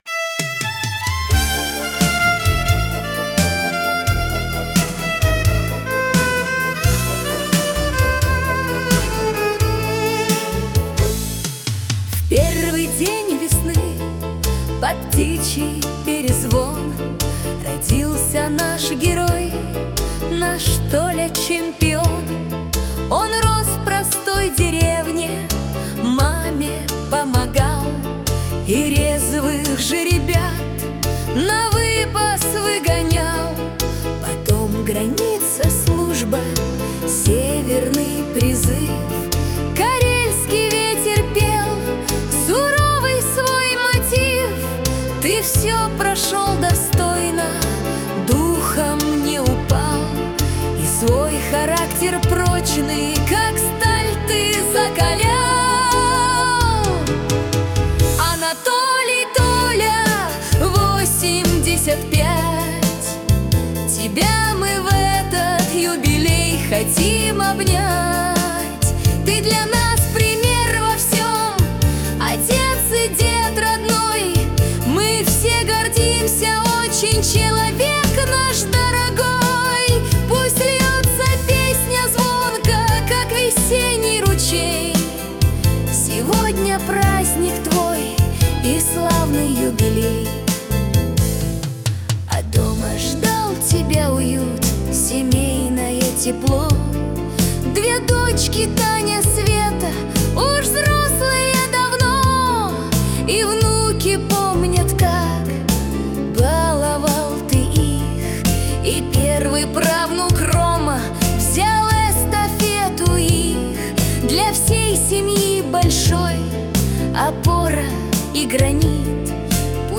Поздравление на юбилей для дедушки в стиле Шансон